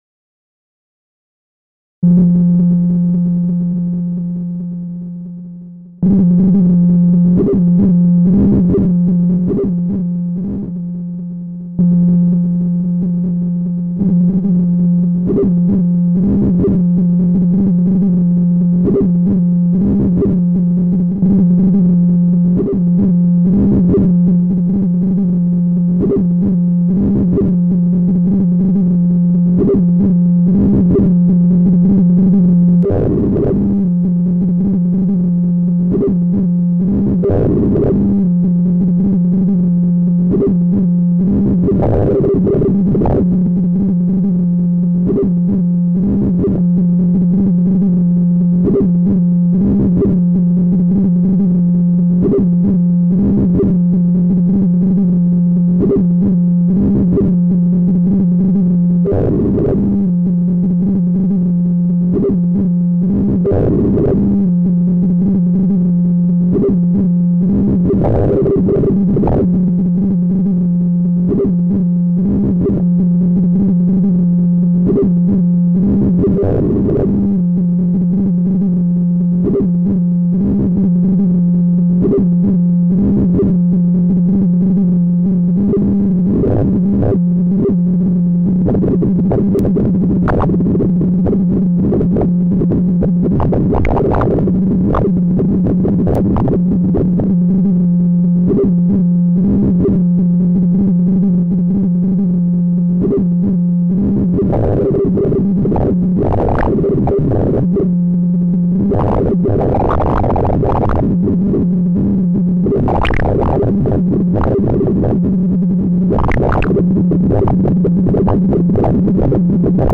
Composition électro-acoustique
mai 2008, révision juillet 2010 - composition acousmatique